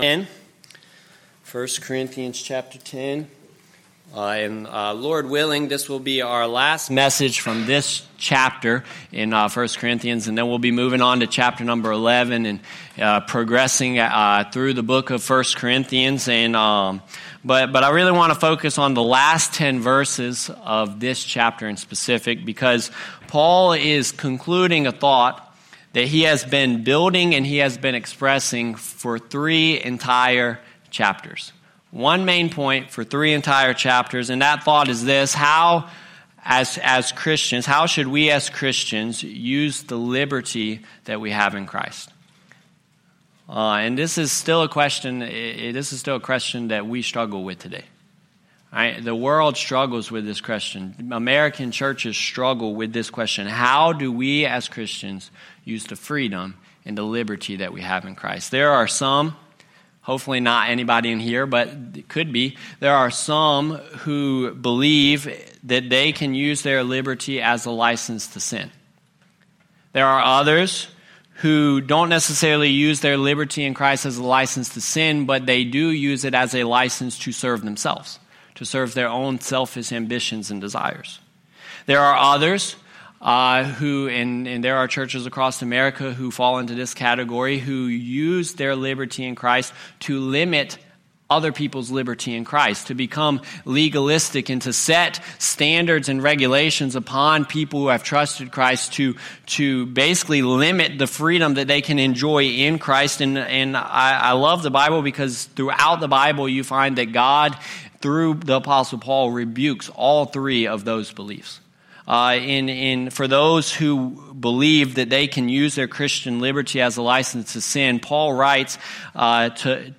Sermons – Bible Baptist Church